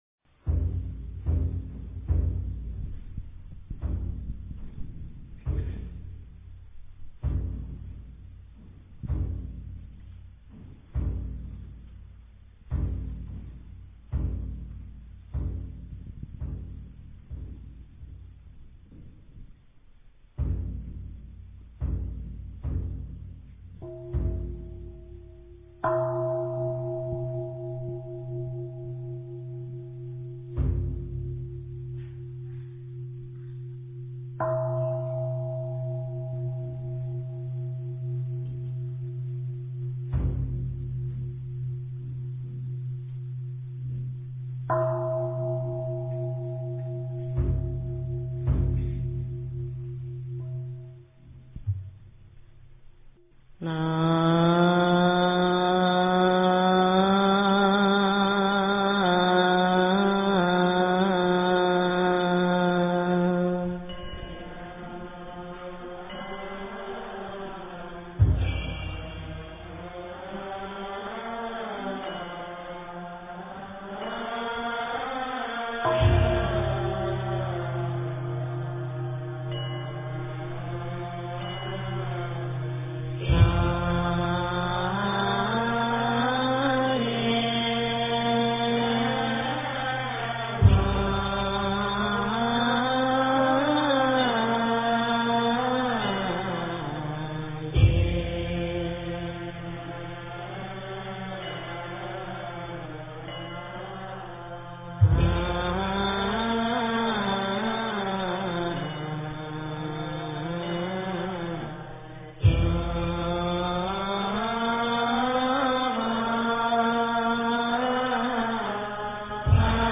八十八佛忏（早课）--普寿寺尼众 经忏 八十八佛忏（早课）--普寿寺尼众 点我： 标签: 佛音 经忏 佛教音乐 返回列表 上一篇： 净宗早课--未知 下一篇： 炉香赞--文殊院 相关文章 往生咒--僧团 往生咒--僧团...